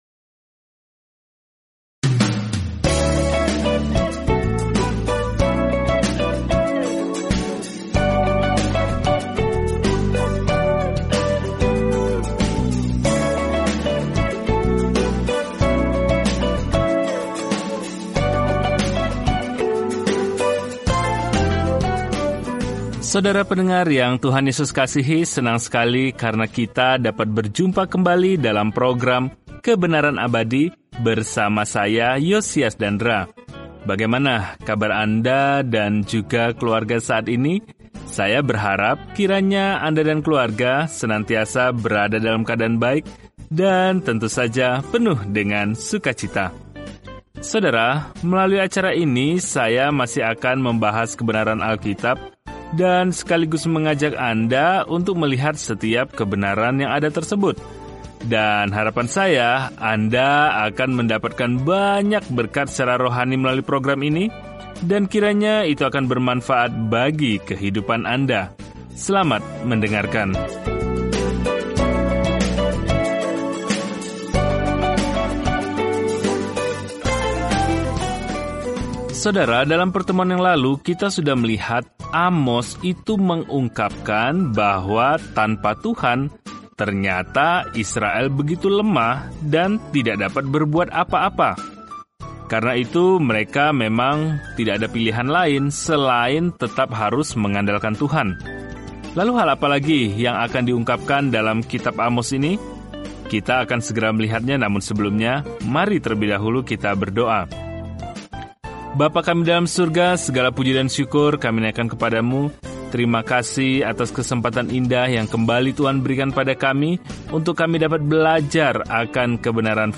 Jelajahi Amos setiap hari sambil mendengarkan studi audio dan membaca ayat-ayat tertentu dari firman Tuhan.